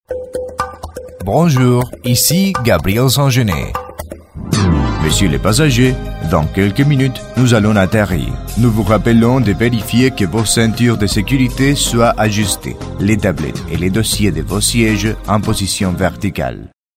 LOCUTOR PREMIUM
OBSERVACIONES: Voz versátil para todo tipo de grabaciones en diversos idiomas.
DEMO FRANCÉS: